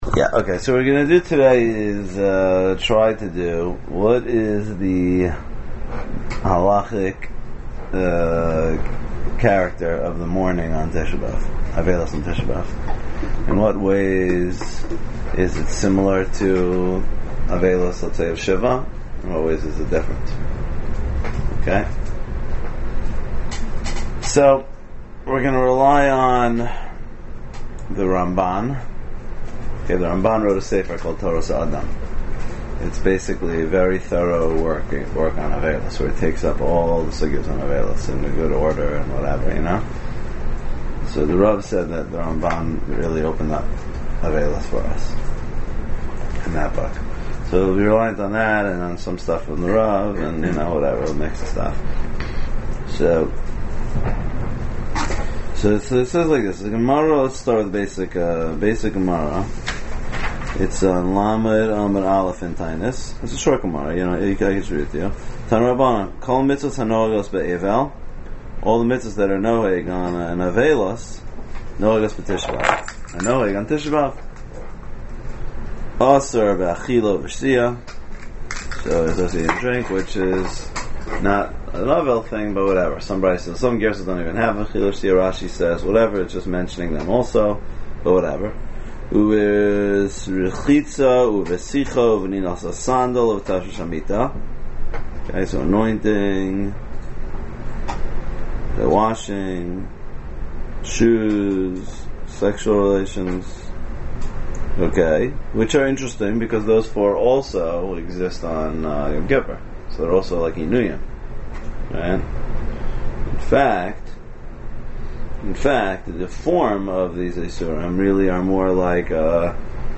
Shiurim